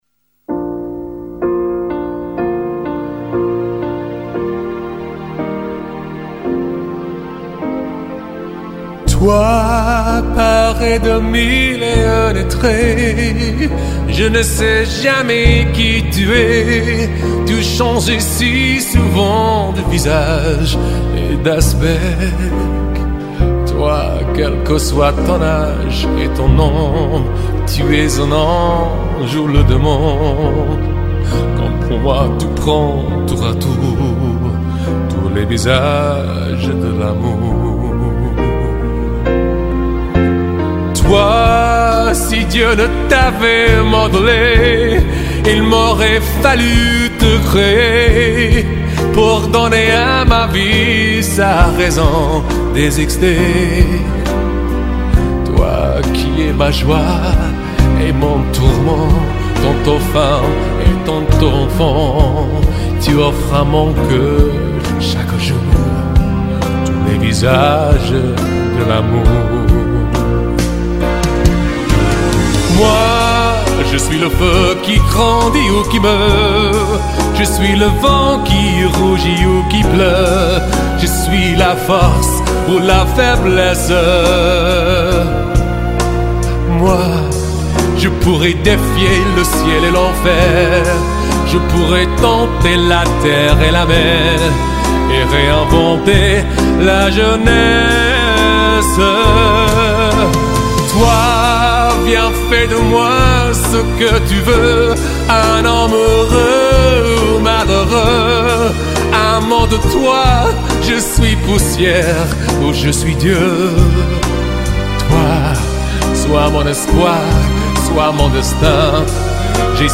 classic love songs